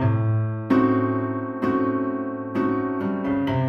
Index of /musicradar/gangster-sting-samples/130bpm Loops
GS_Piano_130-A2.wav